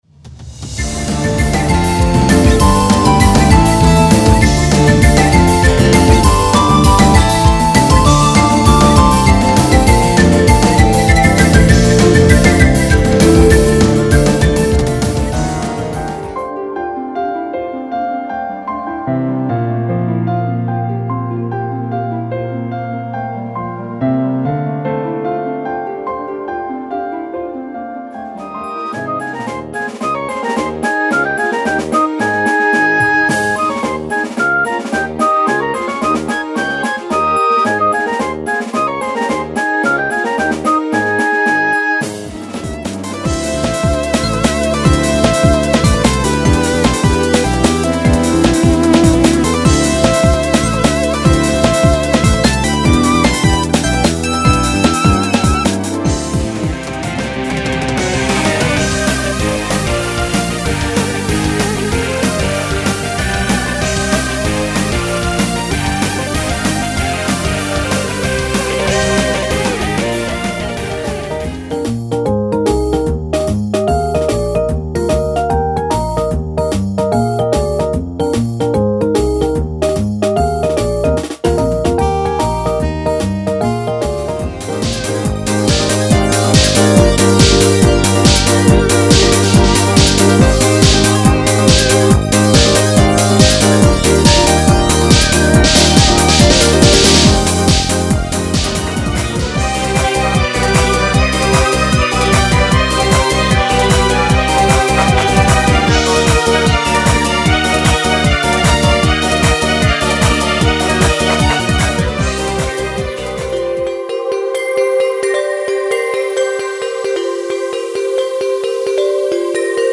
クロスフェードデモMP3で試聴する